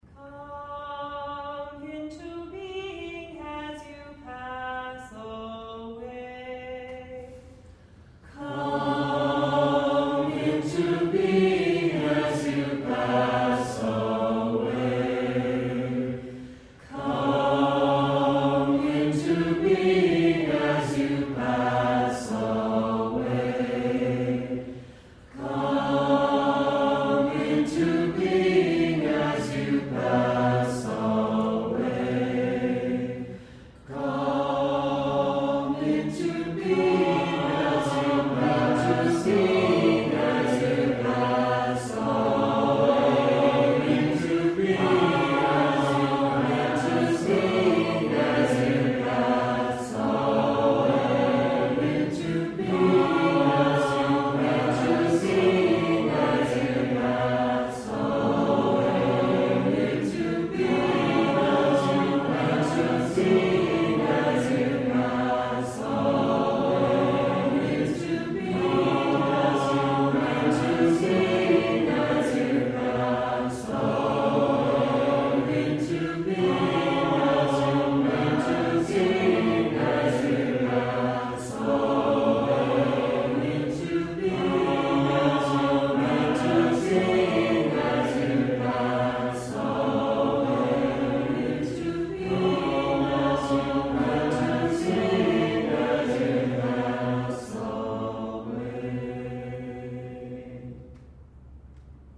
Chant: